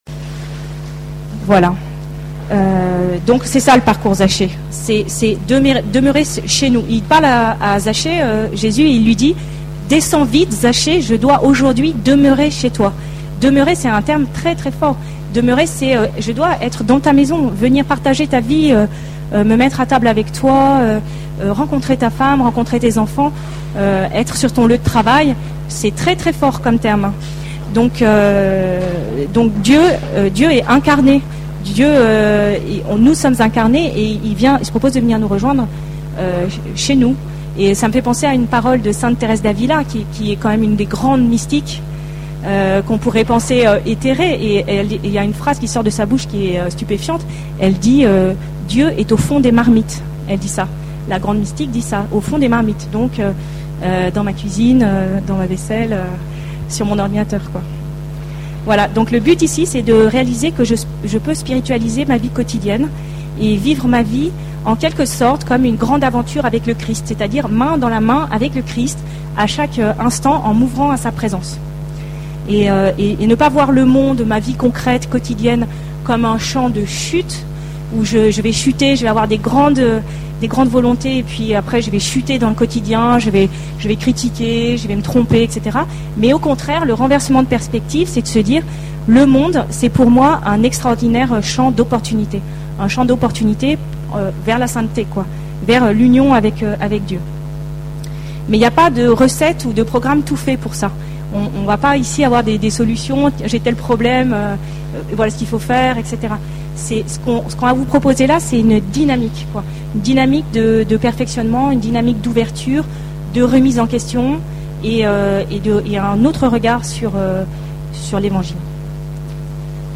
Enseignement
Paray-le-Monial, du 12 au 17 juillet 2013
Format :MP3 64Kbps Mono